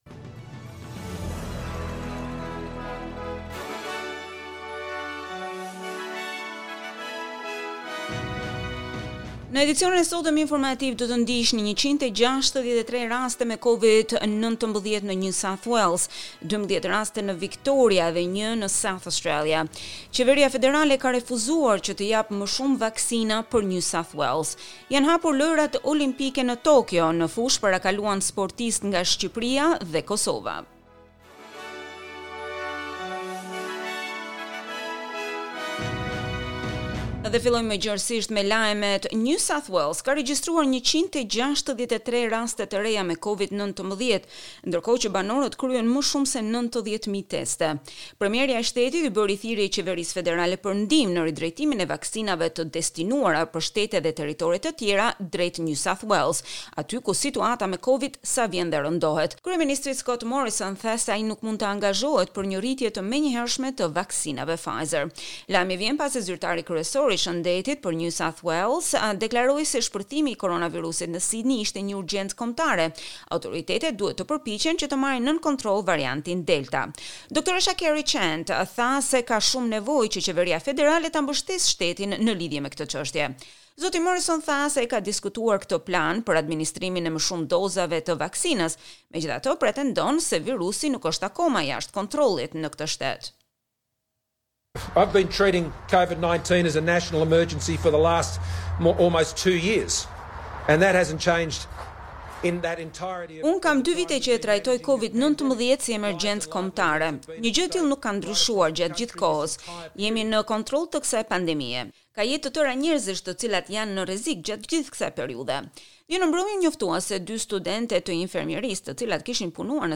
SBS News Bulletin in Albanian - 24 July 2021